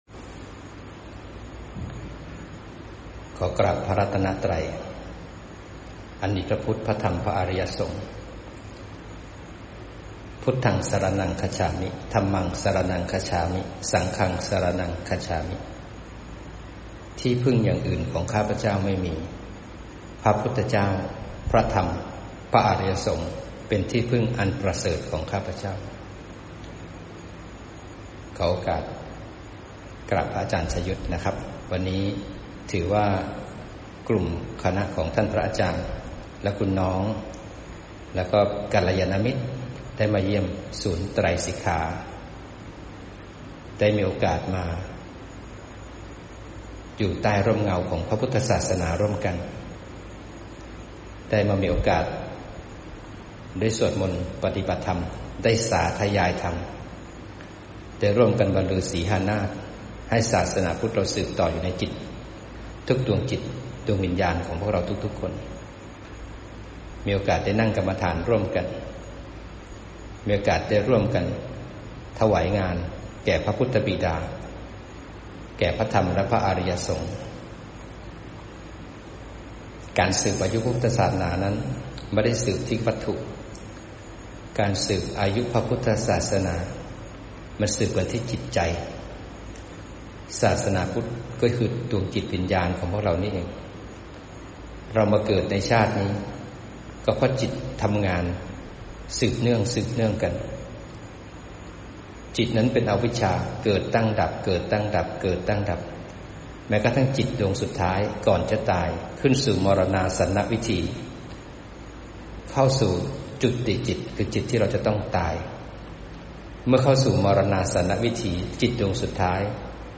ธรรมะที่ต้อนรับกัลยาณมิตรมาเยี่ยมที่ไตรสิกขาเพชรบุรี